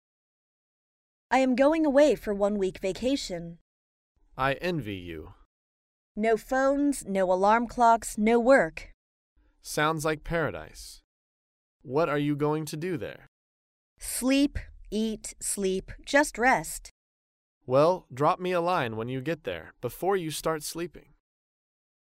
在线英语听力室高频英语口语对话 第360期:度假寄信的听力文件下载,《高频英语口语对话》栏目包含了日常生活中经常使用的英语情景对话，是学习英语口语，能够帮助英语爱好者在听英语对话的过程中，积累英语口语习语知识，提高英语听说水平，并通过栏目中的中英文字幕和音频MP3文件，提高英语语感。